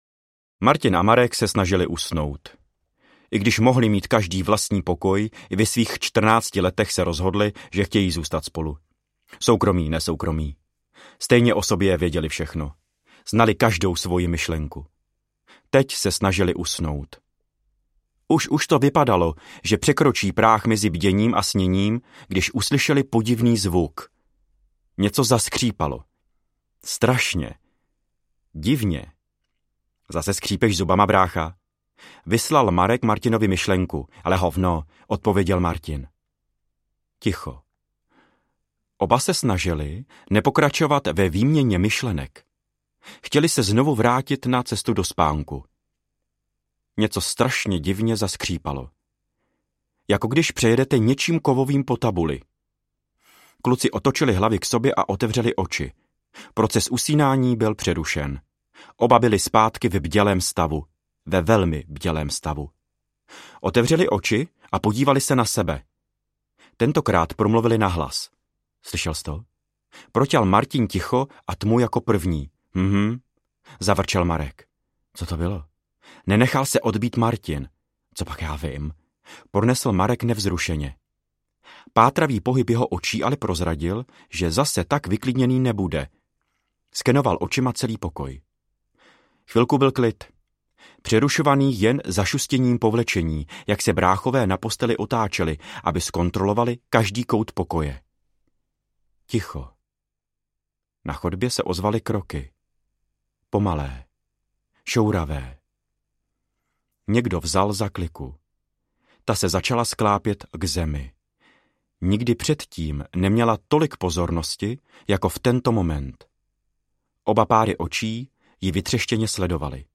Baba Jaga audiokniha
Ukázka z knihy